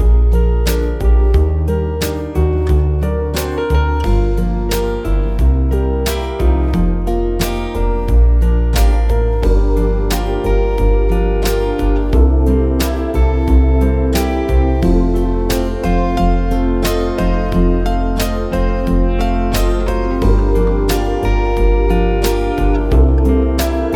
For Solo Singer Easy Listening 2:59 Buy £1.50